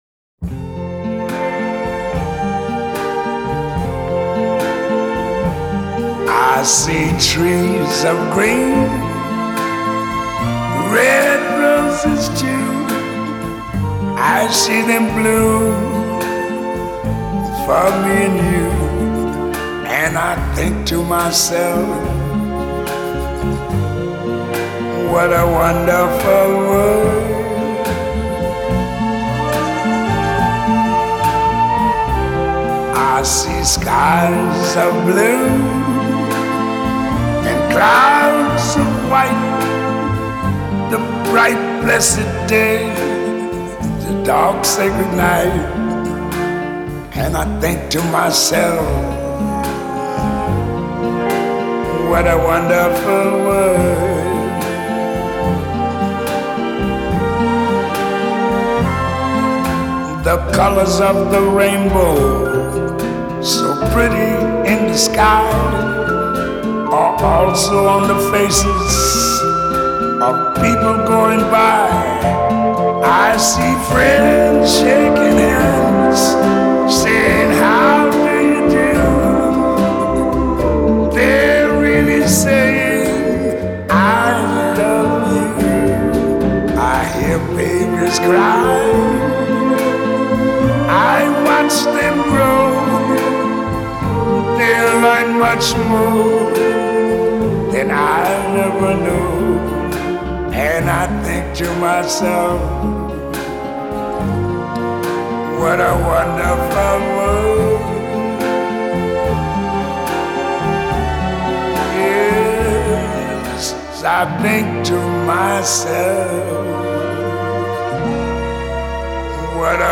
cantante española